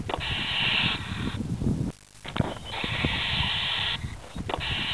Una Cinciarella ha fatto il nido in una profonda cavità in un muro del pollaio.I suoi piccoli emettono un caratteristico cinguettio quando i genitori entrano nel nido. Se un predatore cerca di penetrare nel nido i piccoli e/o gli adulti emettono un minaccioso soffio, che somiglia a quello di un rettile, per far fuggire l'intruso.
Soffio (200 KB),
cinciarellasndred.wav